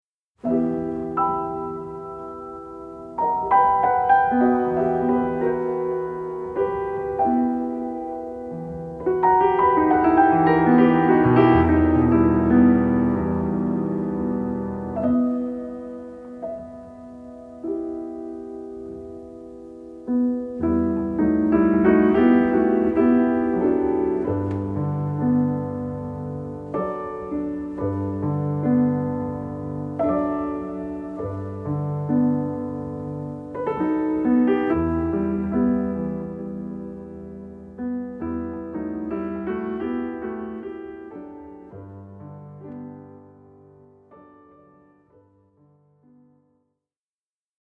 14 Piano Solos